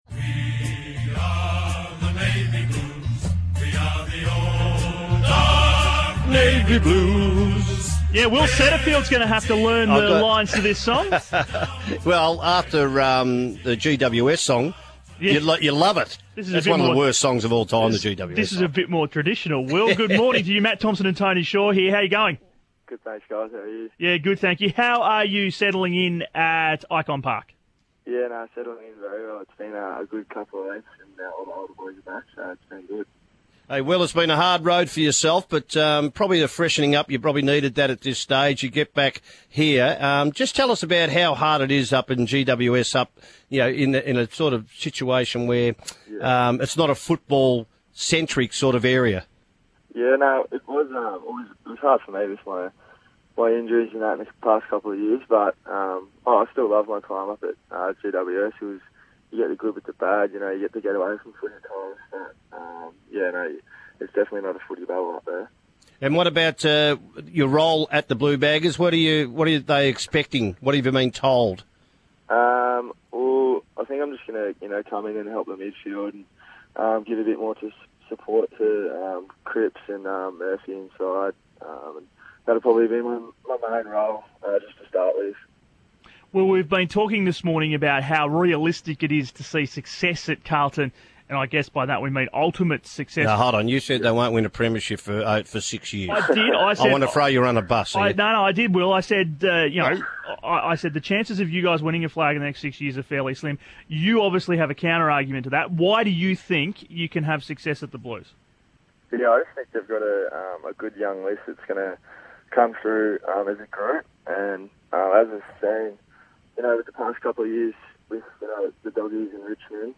New Blue Will Setterfield chatted with the team on Macquarie Sports Radio this morning about how he is settling in at Carlton.